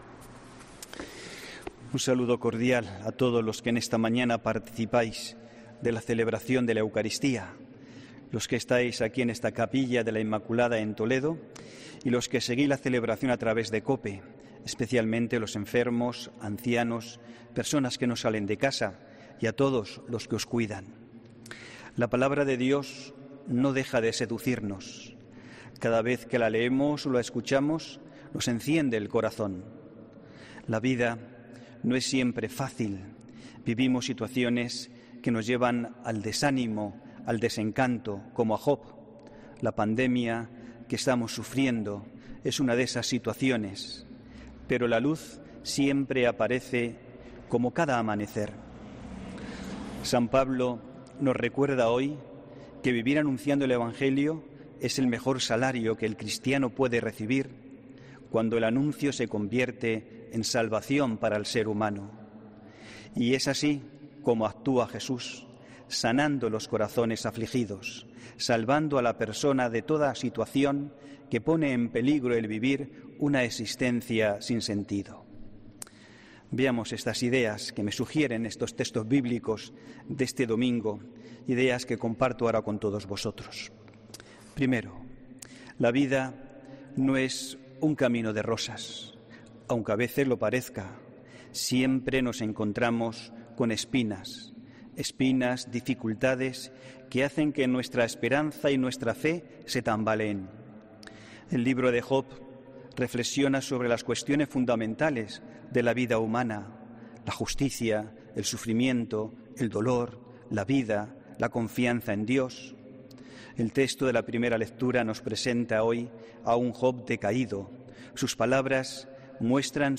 HOMILÍA 7 FEBRERO 2021